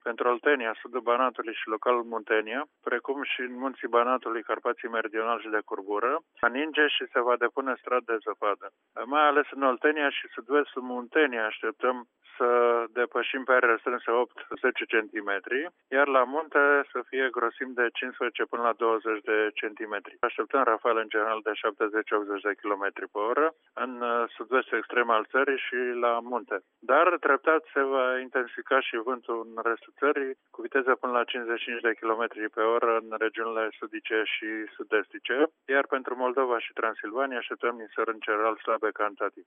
Are detalii meterorologul de serviciu